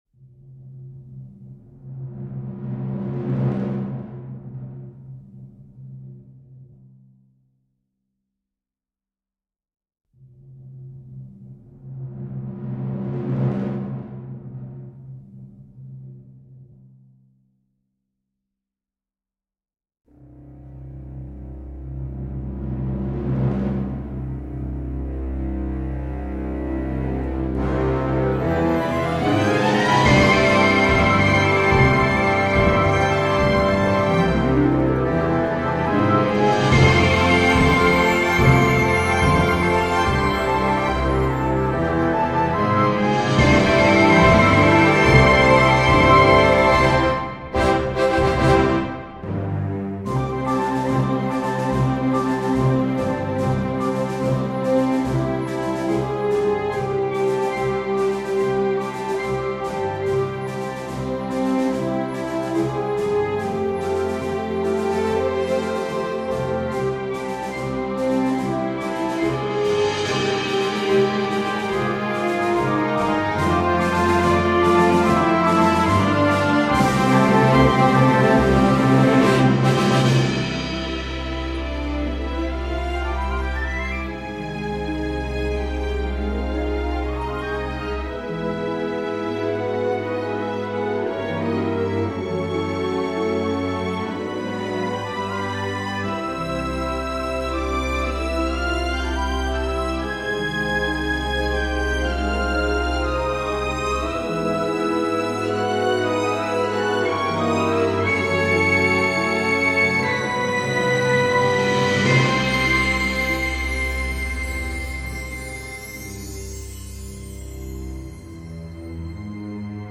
A New Orchestral work about the Ocean | Feedback Wanted